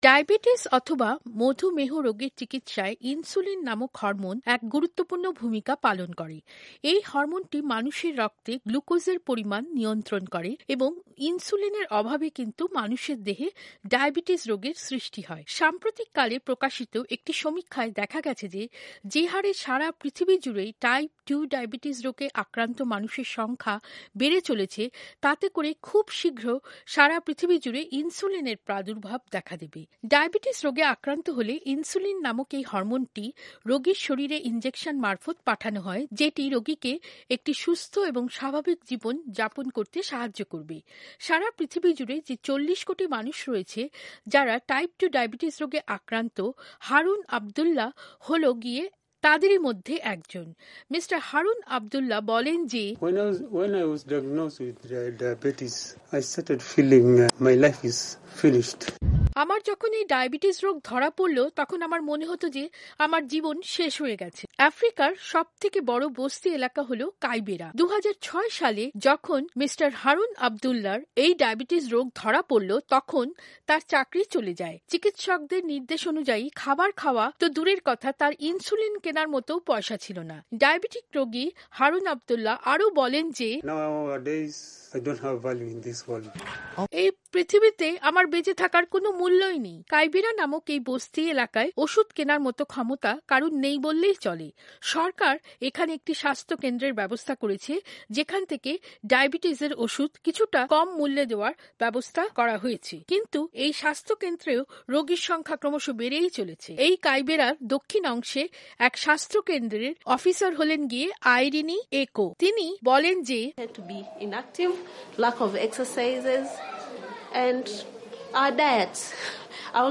স্বাস্থ্য ও বিজ্ঞান পর্বে প্রতিবেদনটি পঅরে শোনাচ্ছেন